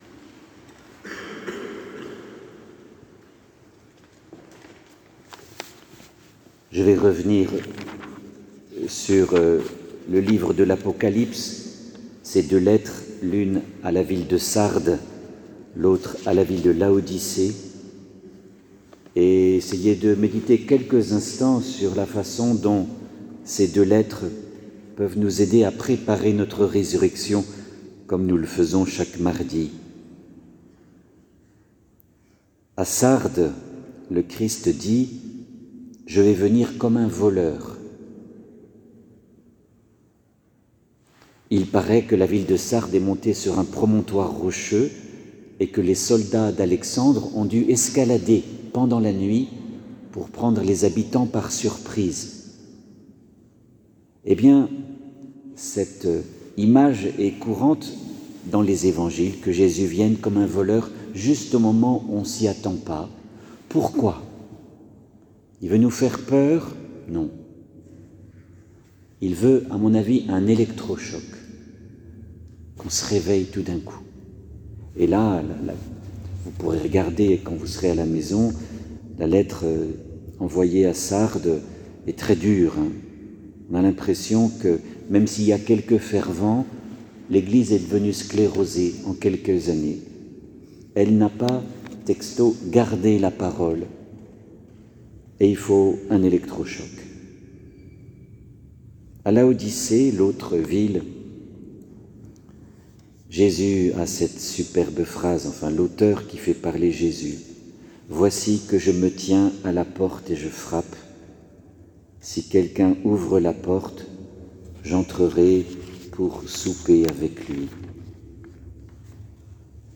les homélies « Préparons notre Résurrection » – Eglise Saint Ignace